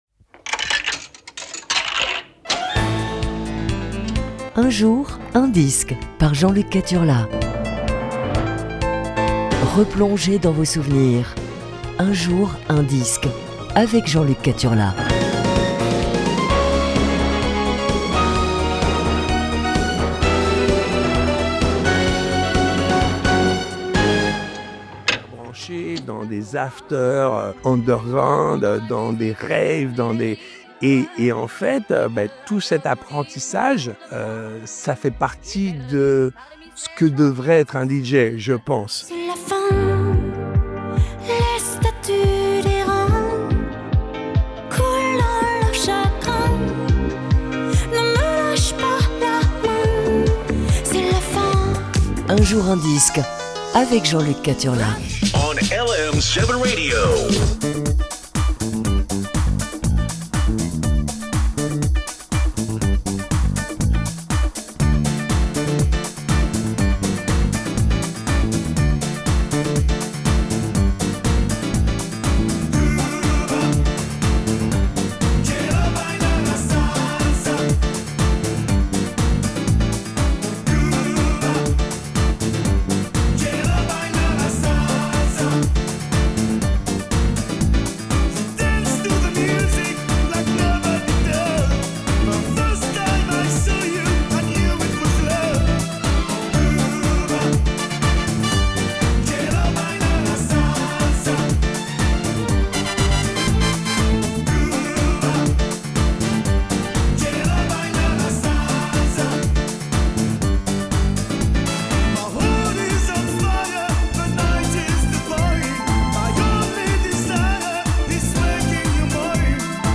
Aujourd'hui c'est Disco Funk 5/5